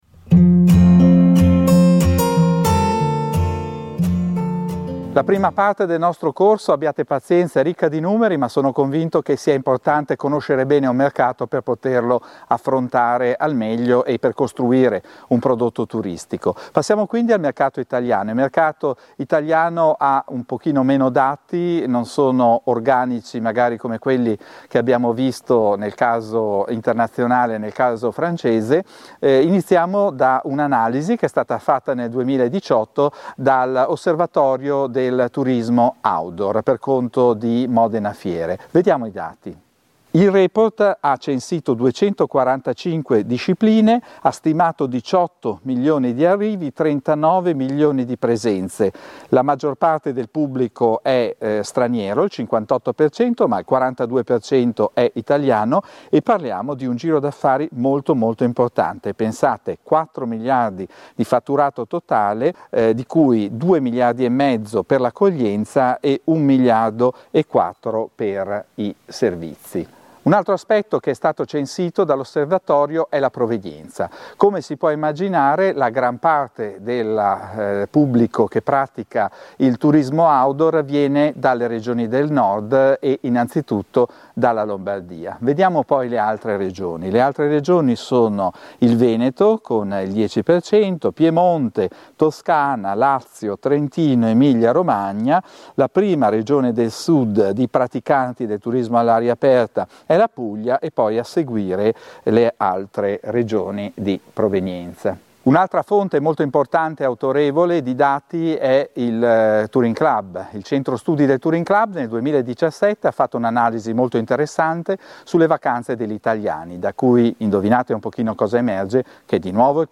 Lezione 4